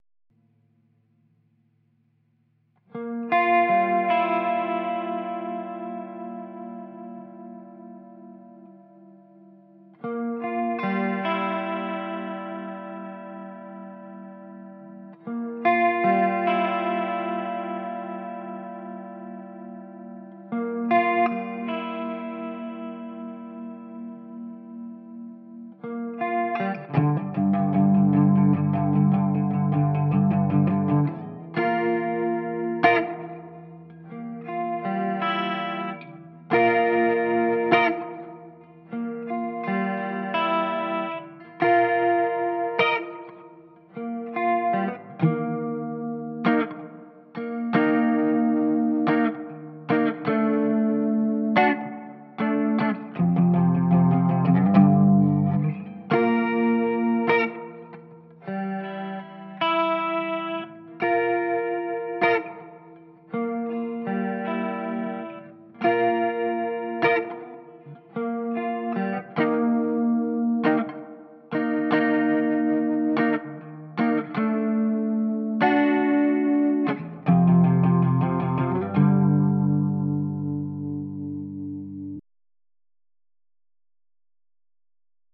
C'est pour tester le son clair